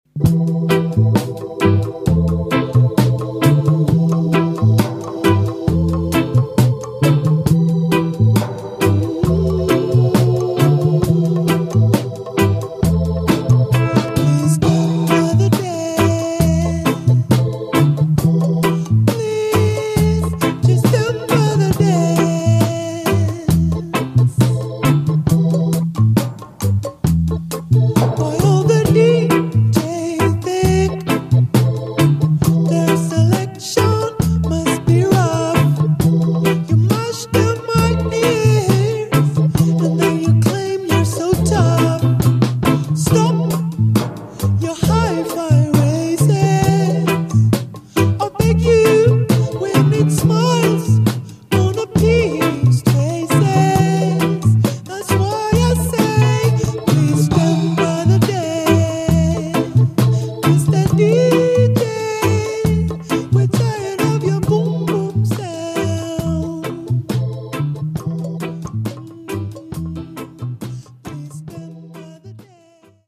strictly roots !!